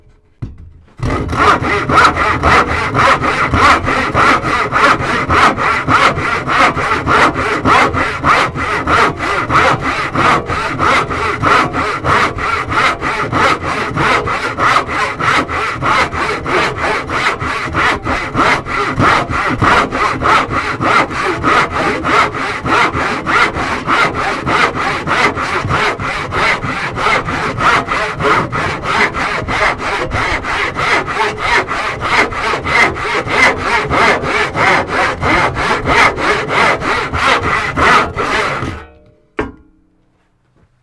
Звуки ножовки
На этой странице собраны звуки ножовки в разных вариациях: от плавных движений по дереву до резких рывков при работе с металлом.